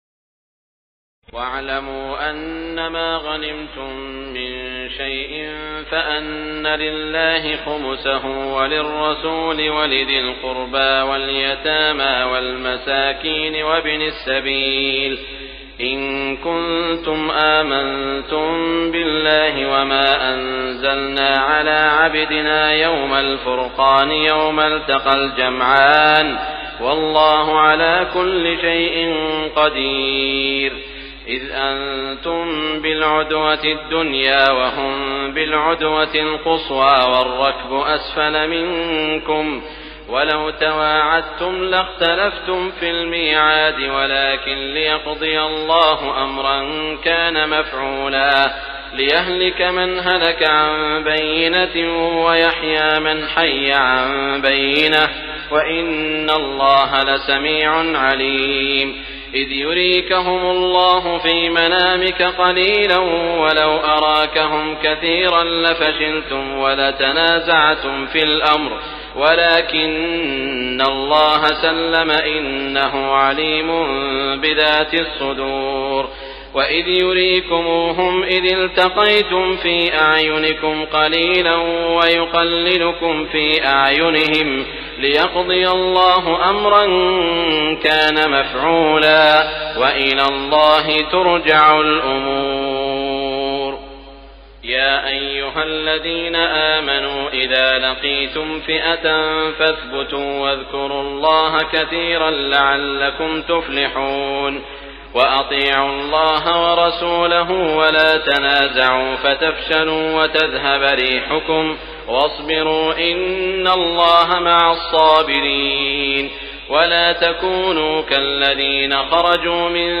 تراويح الليلة العاشرة رمضان 1418هـ من سورتي الأنفال (41-75) و التوبة (1-37) Taraweeh 10 st night Ramadan 1418H from Surah Al-Anfal and At-Tawba > تراويح الحرم المكي عام 1418 🕋 > التراويح - تلاوات الحرمين